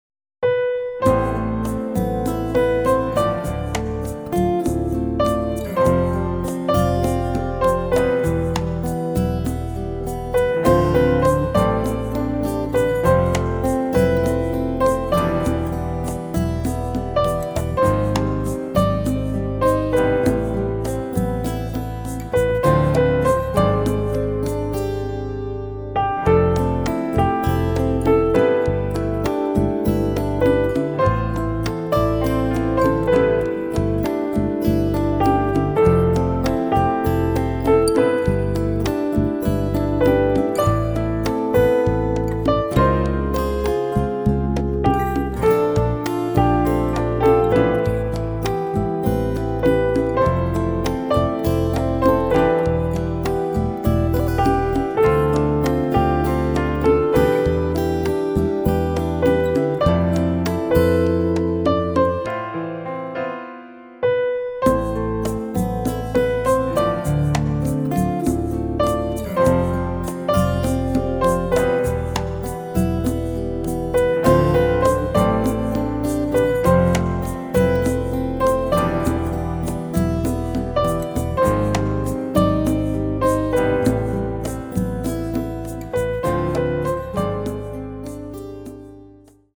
Gitarre
Genre: Chanson